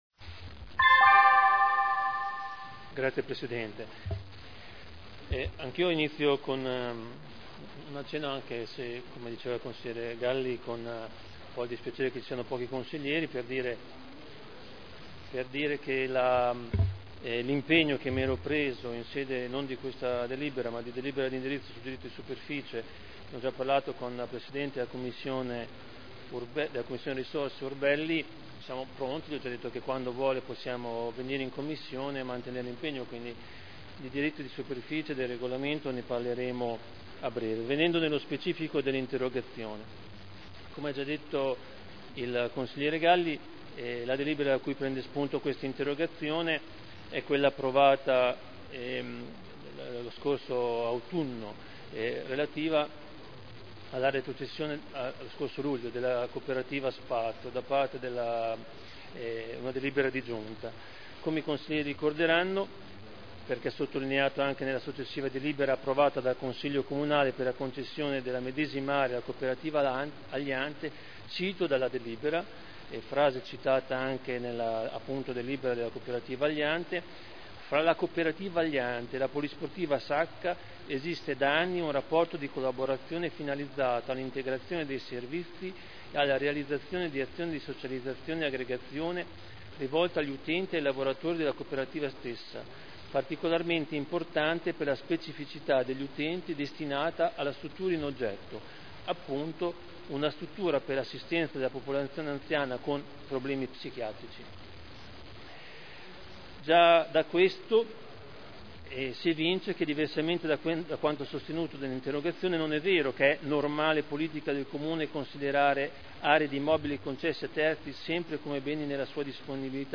Seduta del 24/01/2011. Risponde a interrogazione dei consiglieri Galli, Pellacani, Taddei (PdL) sulla Coop. “Spazio” – Primo firmatario consigliere Galli (presentata il 4 novembre 2010 – in trattazione il 24.1.2011)